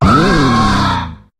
Cri de Corboss dans Pokémon HOME.